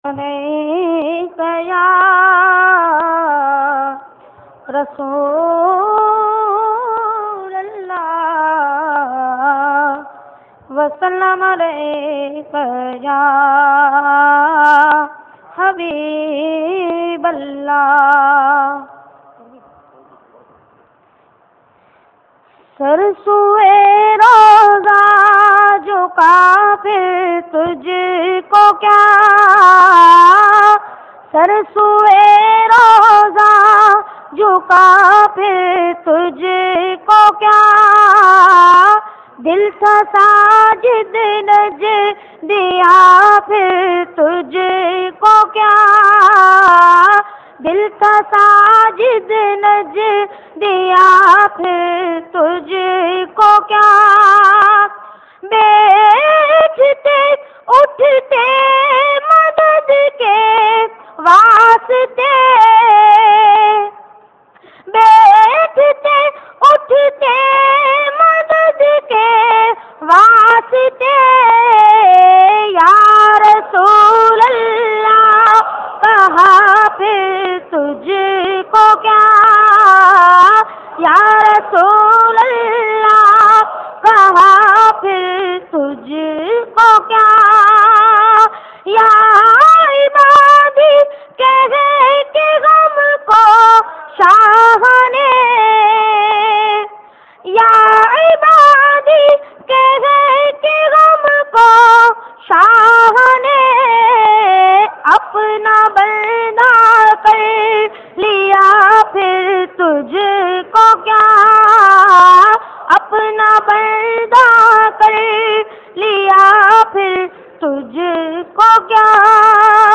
Naat Sharif Sar Sue Roza jhuka phir tujhko kya